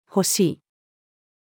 欲しい-female.mp3